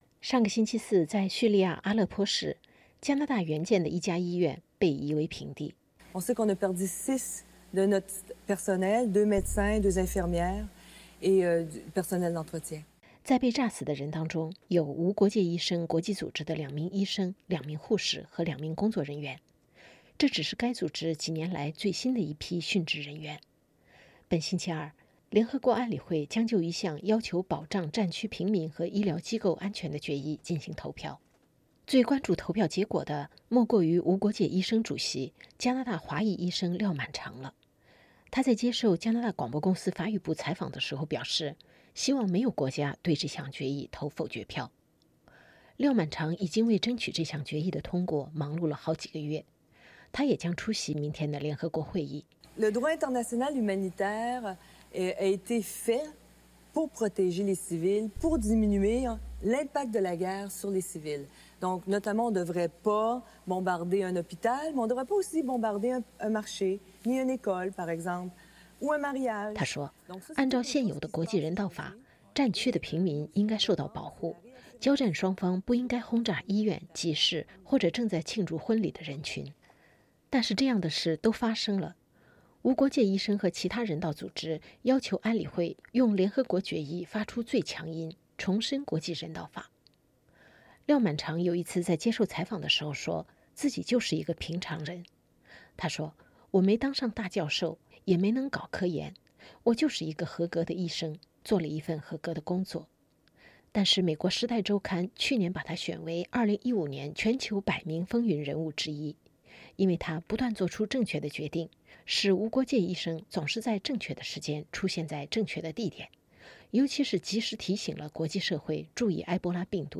她极力控制语气中的愤怒：“我们的患者在病床上被活活烧死了。
Radio-Canada采访廖满嫦（法语）